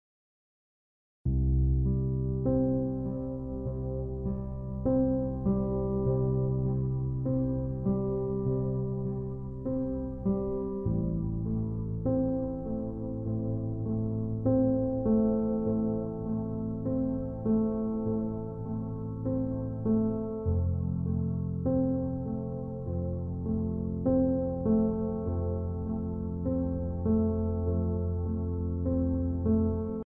Relaxing Violin and Heavenly Cello Music